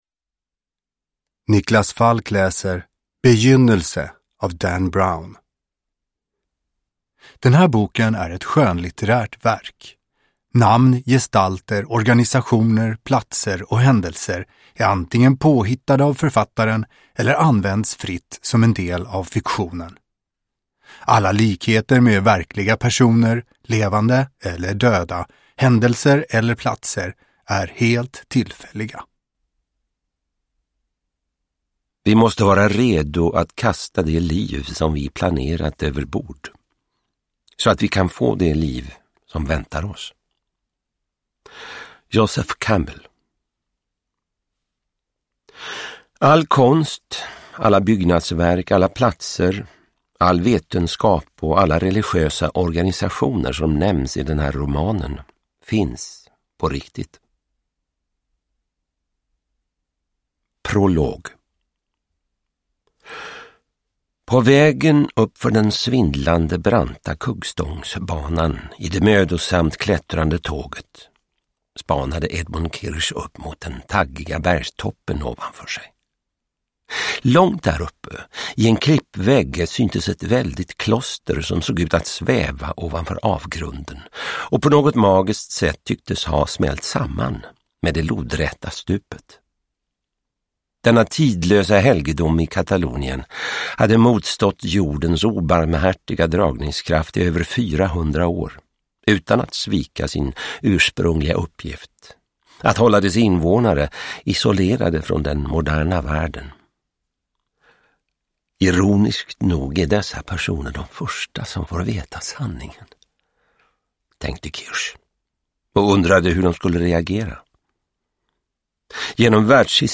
Begynnelse – Ljudbok – Laddas ner
Uppläsare: Niklas Falk